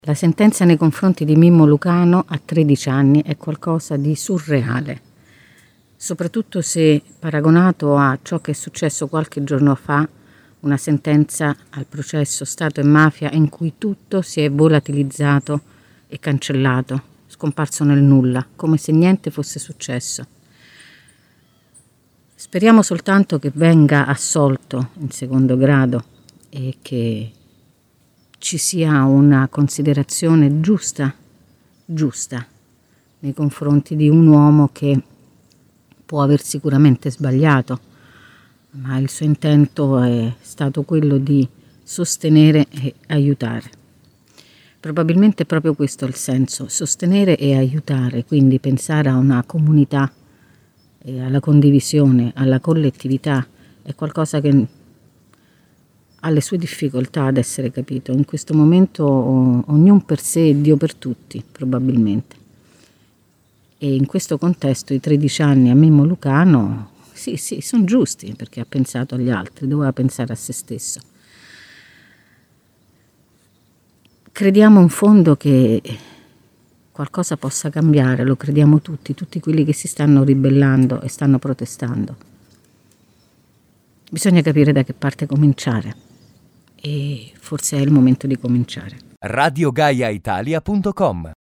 Chi ha conosciuto l’esperimento Riace “sa” che le cose non potevano essere “altro” da ciò che erano (il commento audio